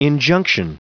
Prononciation du mot injunction en anglais (fichier audio)
Prononciation du mot : injunction